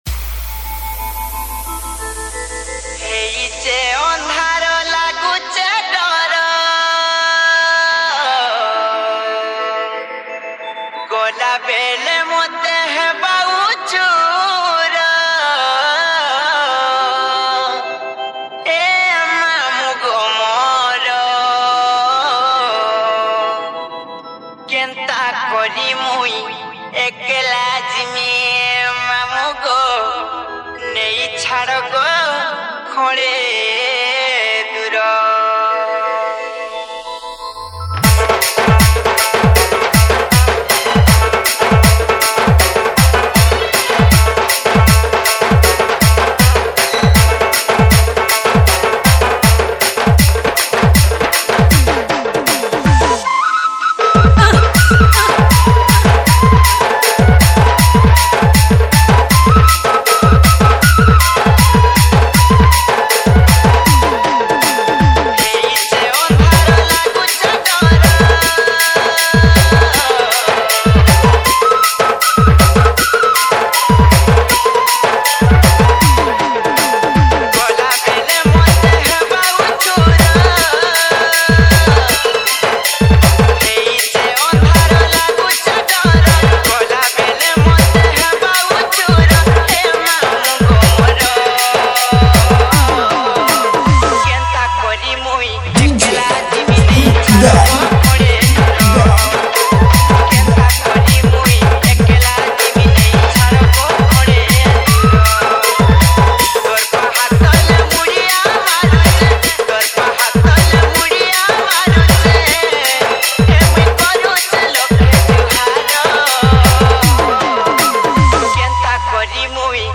SAMBALPURI ROMANTIC DJ REMIX